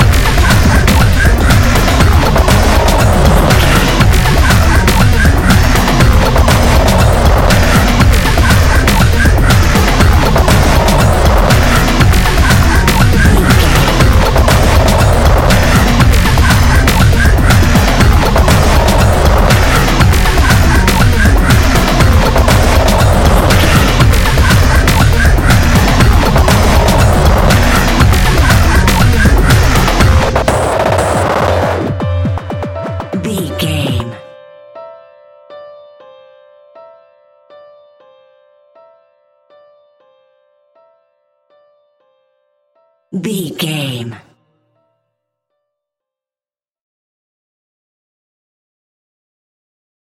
Epic / Action
Fast paced
Atonal
hard
intense
energetic
driving
disturbing
aggressive
dark
piano
synthesiser
drum machine
breakbeat
synth leads
synth bass